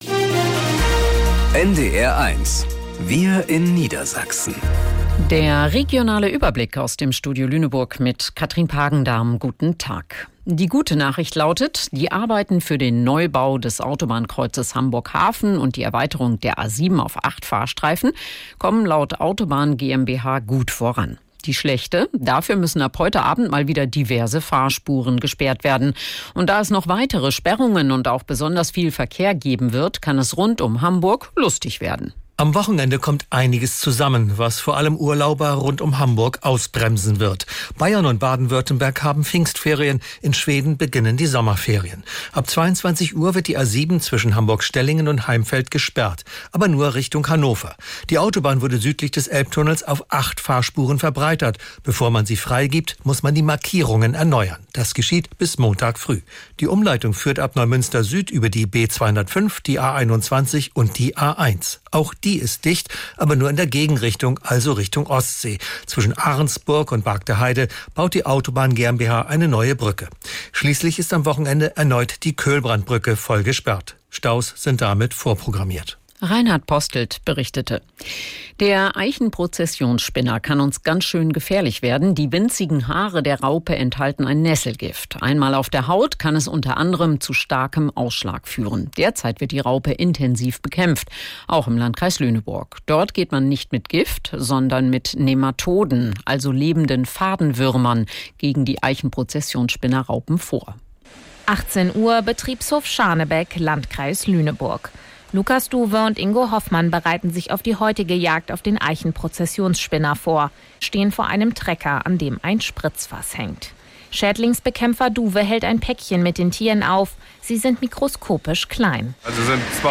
Wir in Niedersachsen - aus dem Studio Lüneburg | Nachrichten